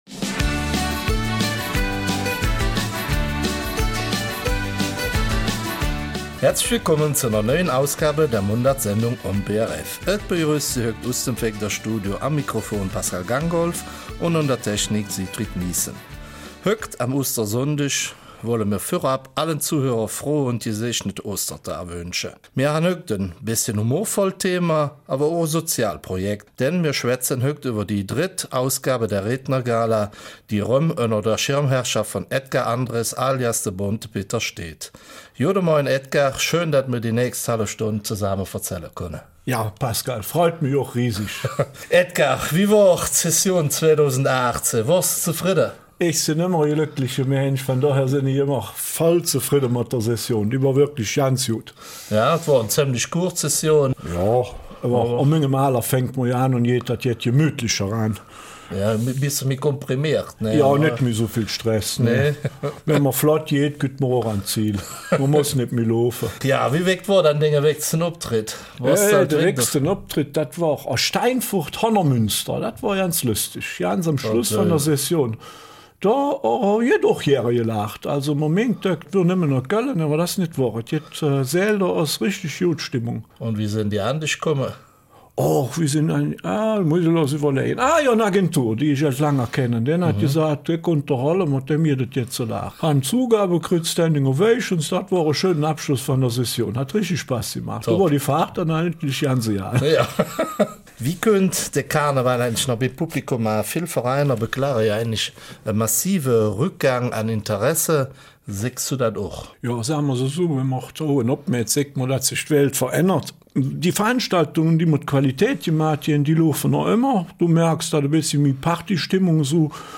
Ein lockeres und humorvolles Interview am Ostersonntag kann ich euch versprechen.